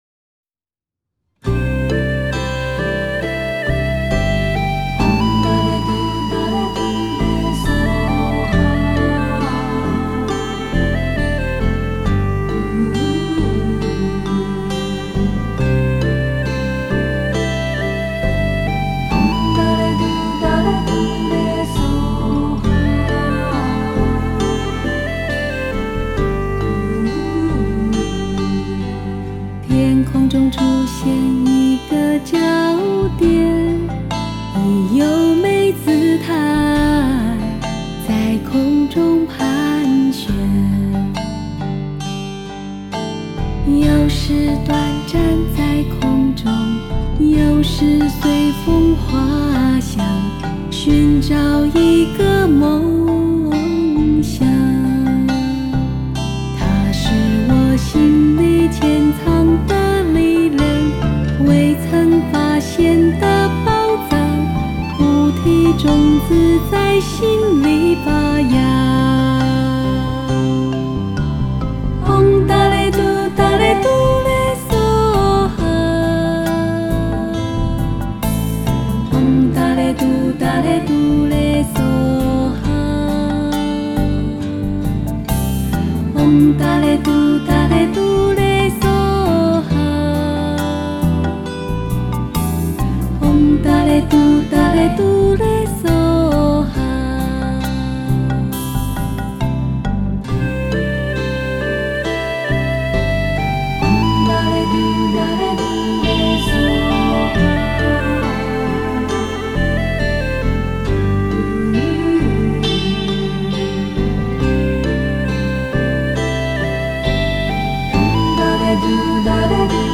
加上慢板的旋律在自由风格里，滴进了一点点忧郁，呈现一种迷离人世的风情